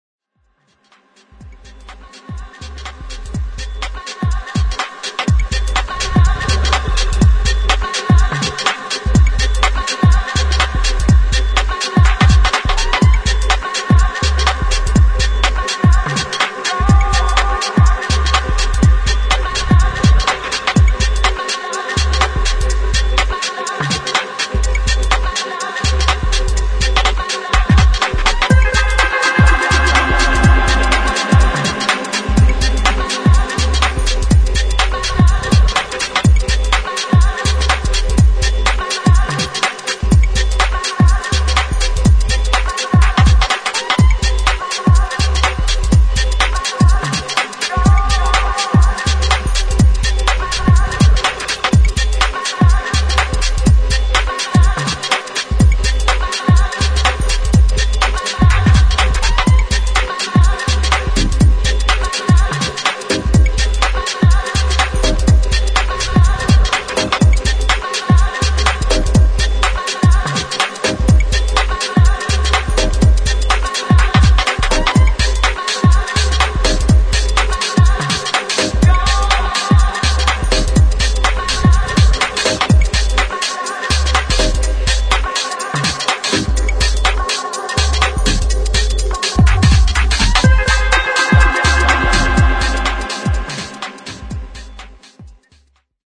[ HOUSE / BASS ]